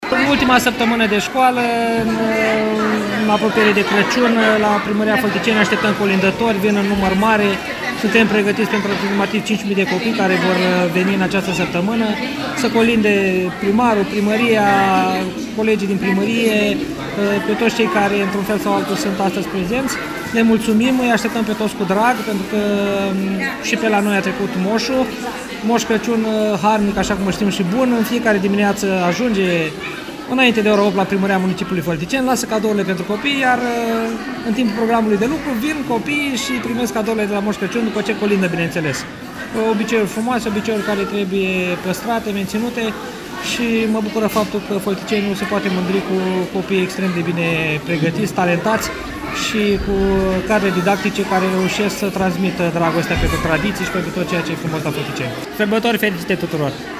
Primarul municipiului, Cătălin Coman, a declarat, pentru Radio VIVA FM, că, până la Crăciun, aşteaptă vizita a 5.000 de copii şi că are cadouri pregătite pentru toţi: